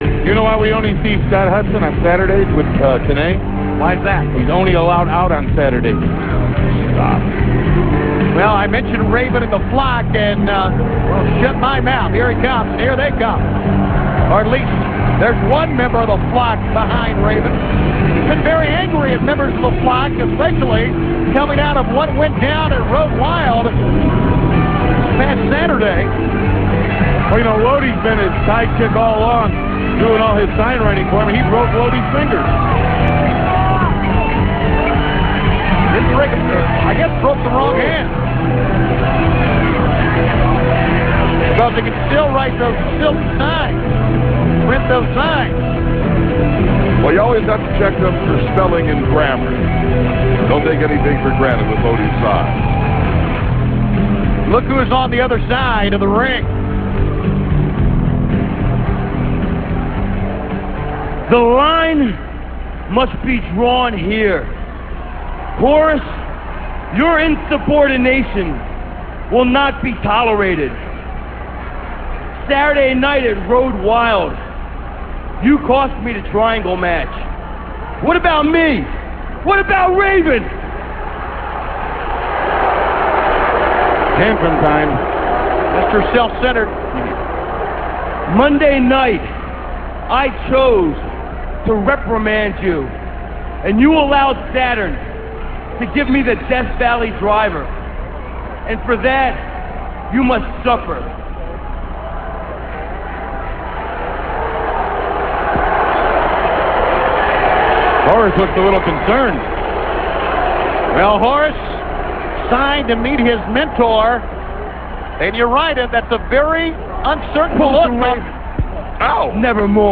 - This speech comes from Thunder - [7.13.98] - Raven draws the line with the Flock & won't tolerate Horace's insubordination anymore.